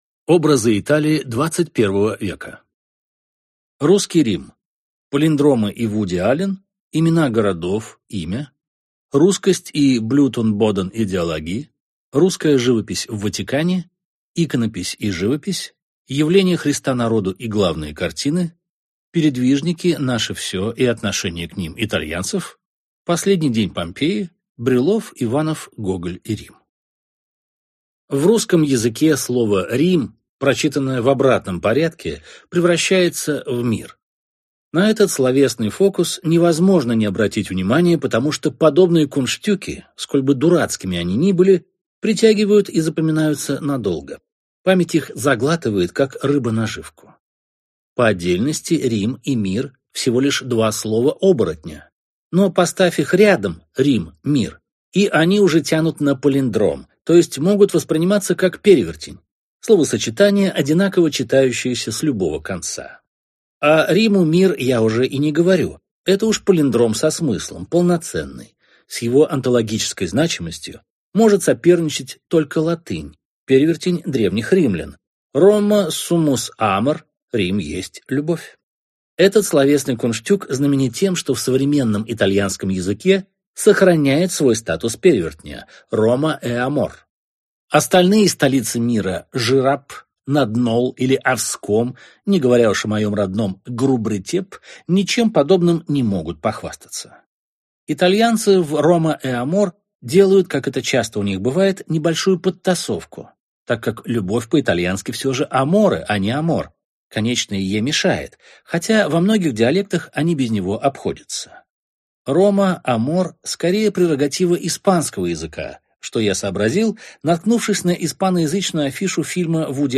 Аудиокнига Просто Рим. Образы Италии XXI | Библиотека аудиокниг